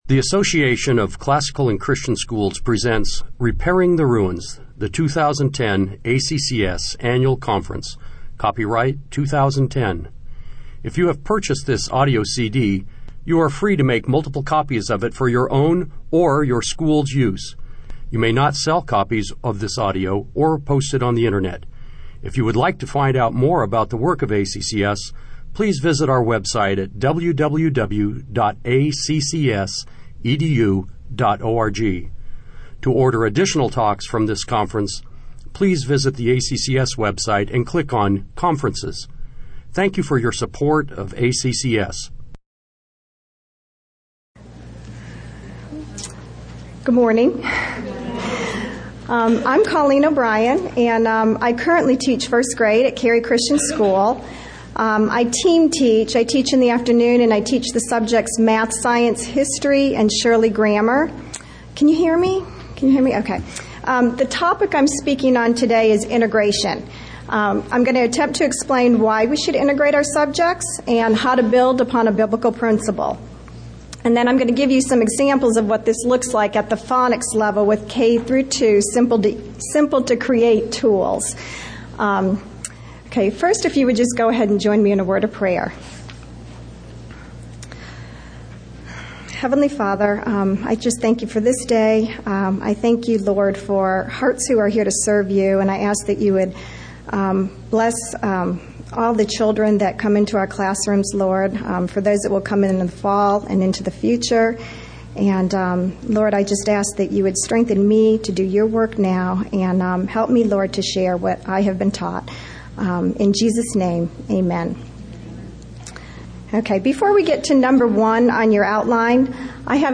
2010 Foundations Talk | 0:51:41 | All Grade Levels